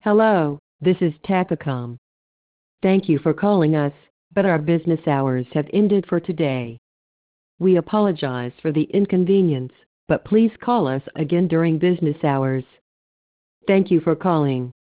• 日本語・英語に対応した高音質「音声合成機能」を標準搭載。
音声合成で作成したサンプル音声を試聴できます
サンプル音源（英語）
gosei_sample_english.wav